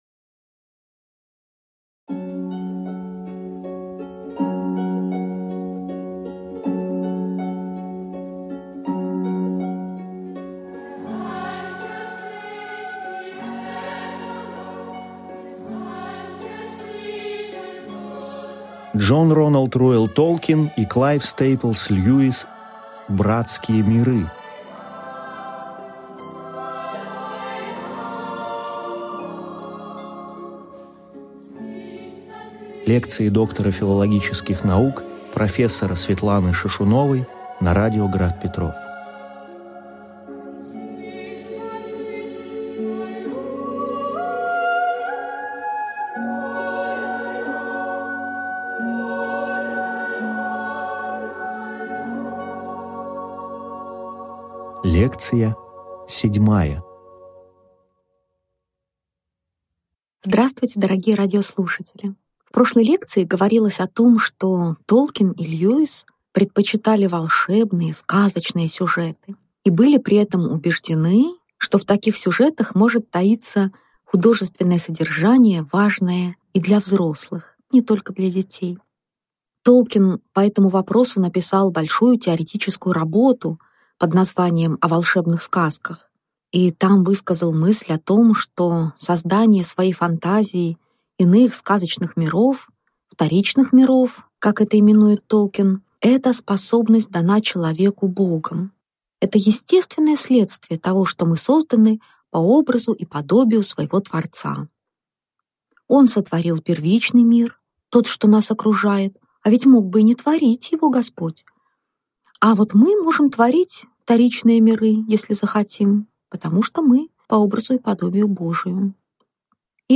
Аудиокнига Лекция 7. Дж.Р.Р.Толкин как создатель языков и мифа | Библиотека аудиокниг